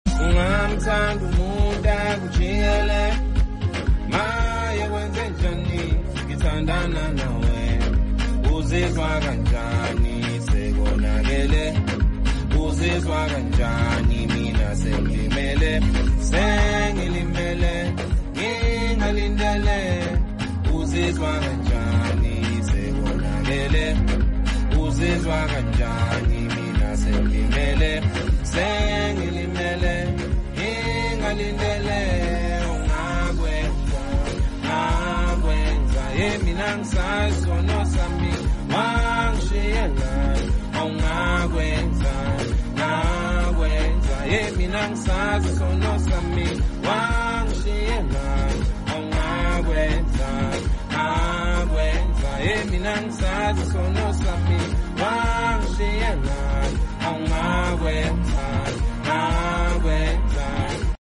#3Step